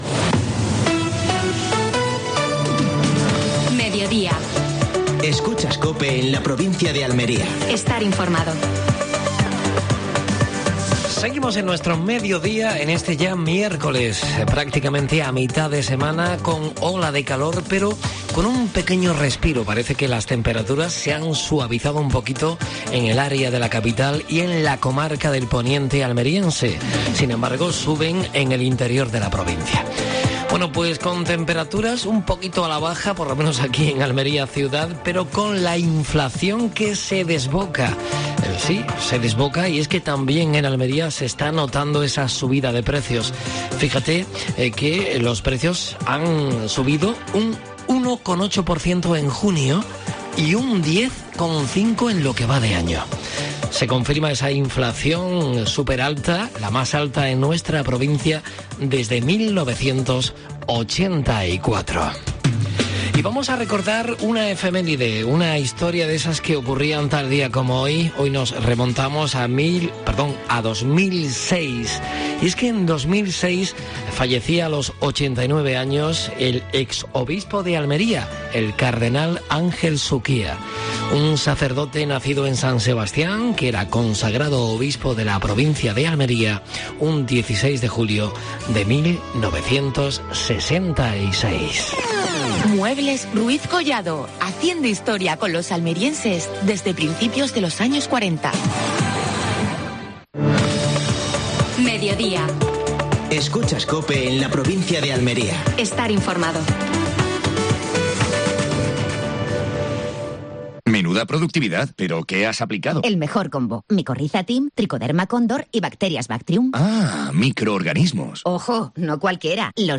Actualidad en Almería. Cursos de Verano de la UAL. Entrevista